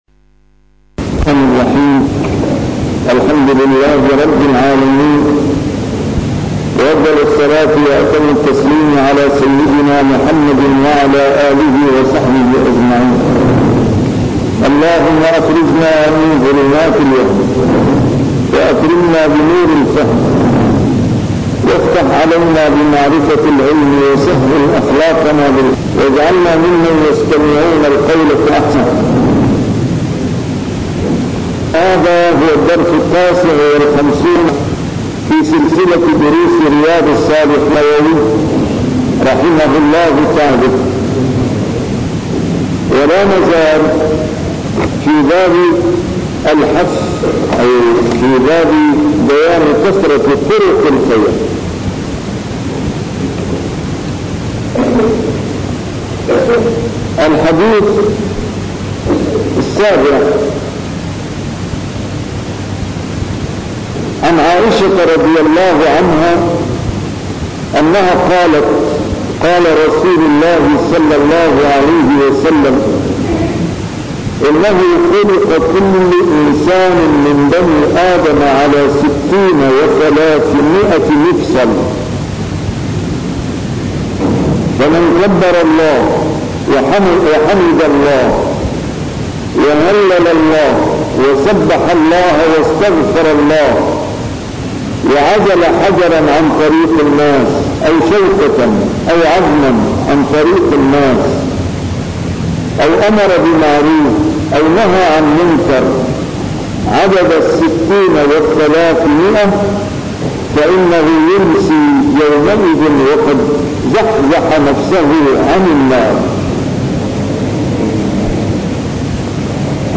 A MARTYR SCHOLAR: IMAM MUHAMMAD SAEED RAMADAN AL-BOUTI - الدروس العلمية - شرح كتاب رياض الصالحين - 159- شرح رياض الصالحين: كثرة طرق الخير